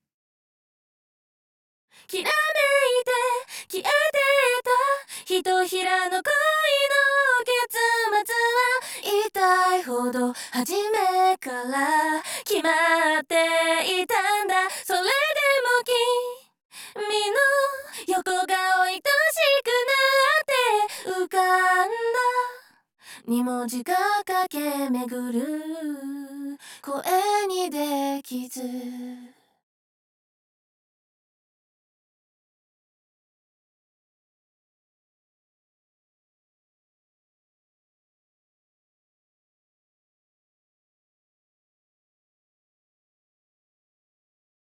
▶Revoice Pro 5で作成したダブルボーカル
このように自然なダブルボーカルが簡単に作成できます。